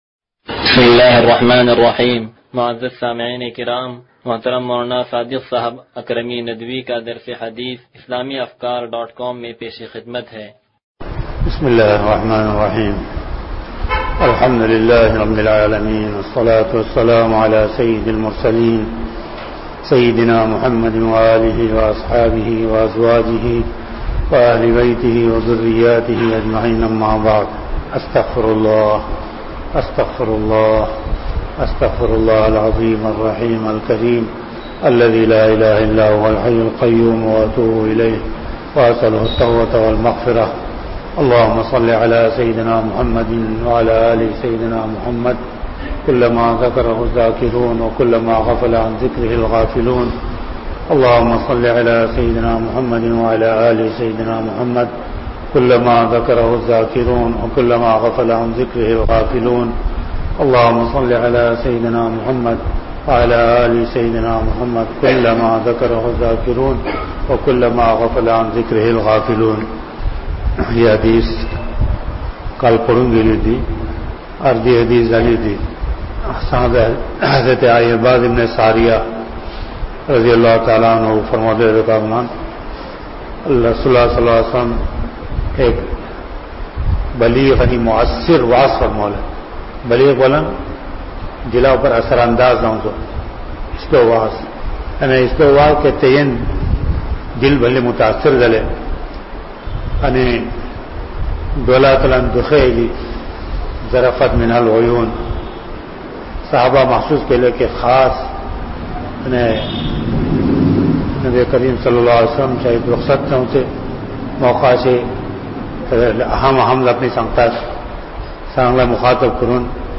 درس حدیث نمبر 0163